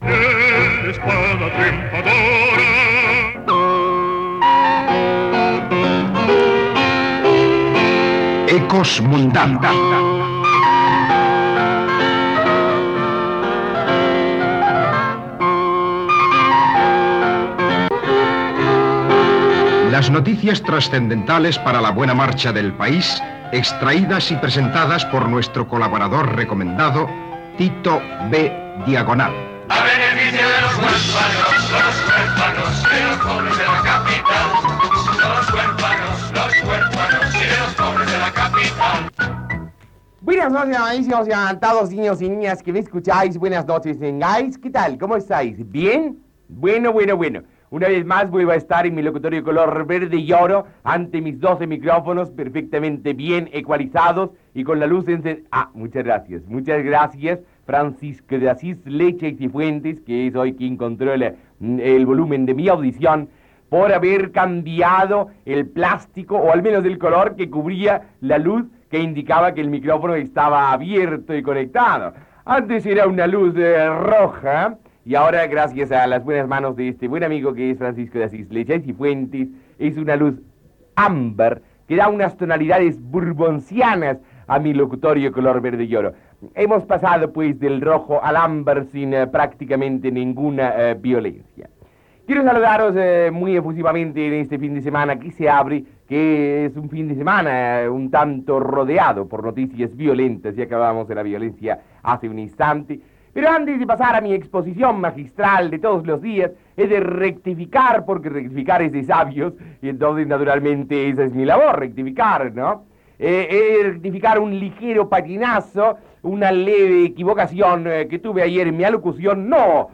bea6f3d259da8b63071b919a5805d7a5ce15cc56.mp3 Títol Radio Juventud RJ2 Emissora Radio Juventud RJ2 Cadena Radiocadena Española Titularitat Pública estatal Nom programa Al mil por mil Descripció Espai "Ecos mundanos". Careta d'entrada del programa
Gènere radiofònic Entreteniment